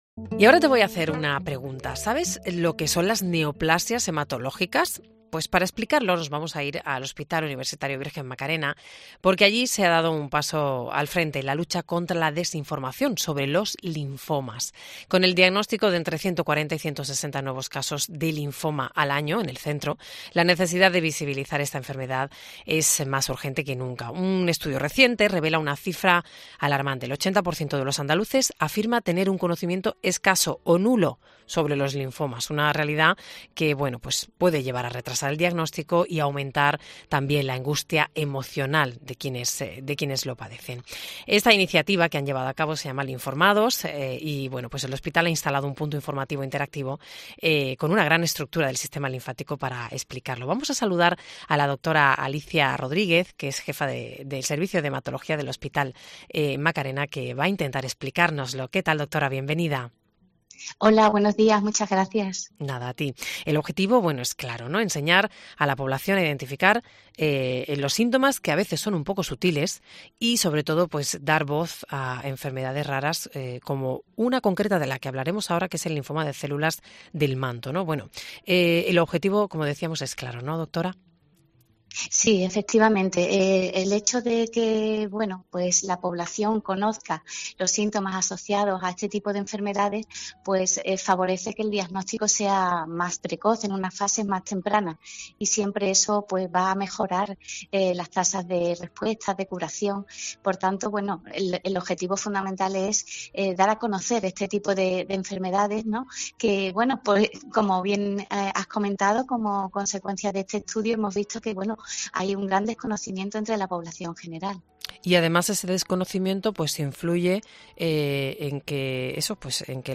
Durante una intervención en el programa Herrera en Cope Más Sevilla, se ha puesto de manifiesto una cifra alarmante: el 80% de los andaluces afirma tener un conocimiento escaso o nulo sobre los linfomas.